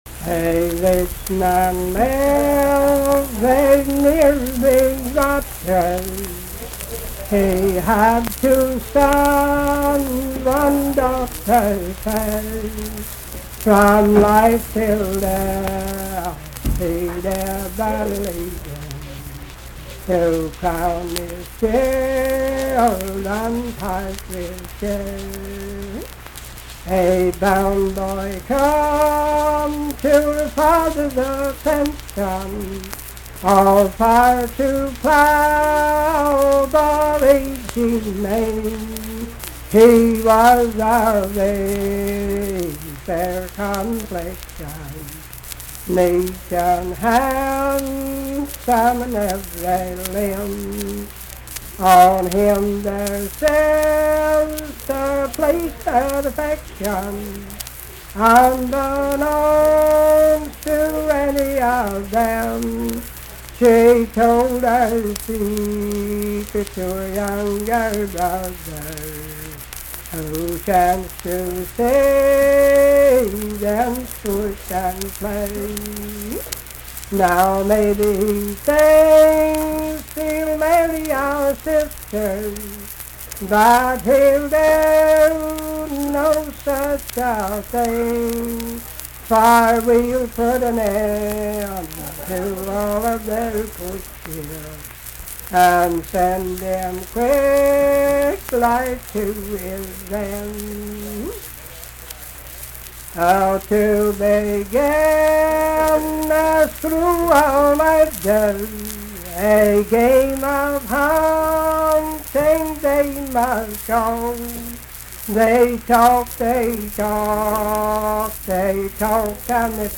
Unaccompanied vocal music
Verse-refrain 14(4).
Performed in Ivydale, Clay County, WV.
Voice (sung)